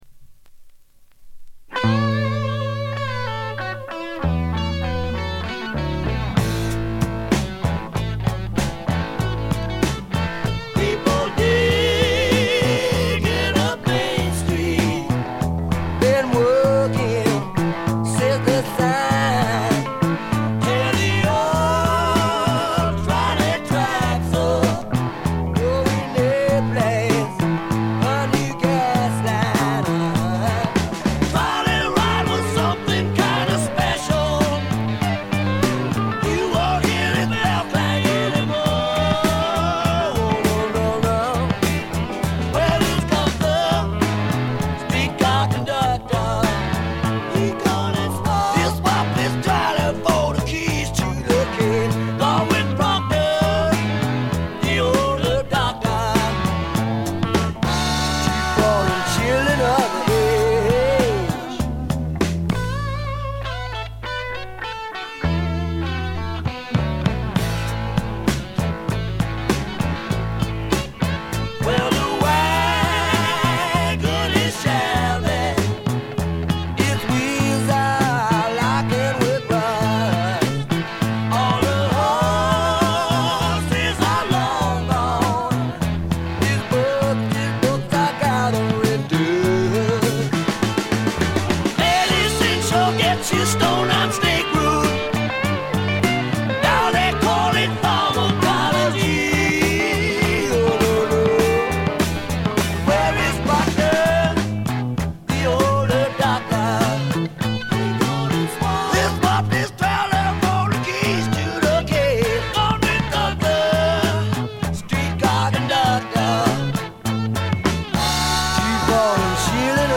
ホーム > レコード：米国 スワンプ
部分試聴ですが、軽微なノイズ感のみ。
試聴曲は現品からの取り込み音源です。